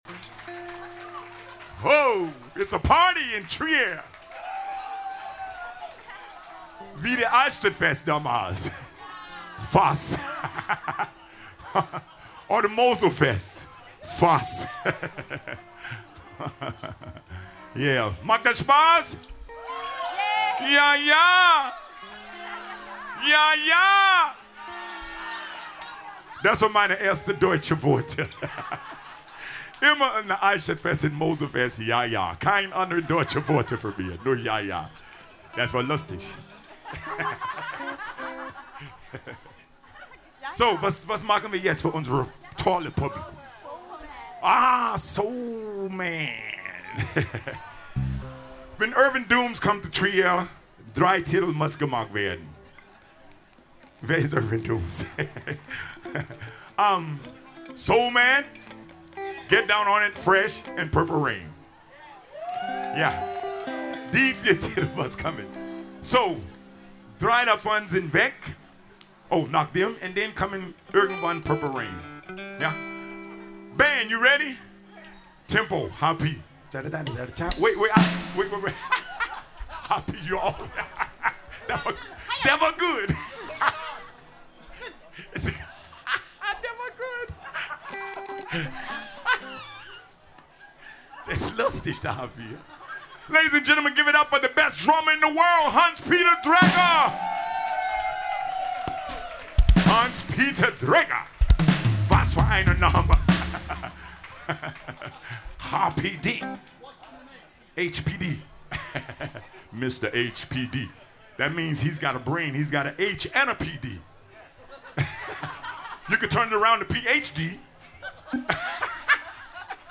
· Genre (Stil): Soul
· Kanal-Modus: stereo · Kommentar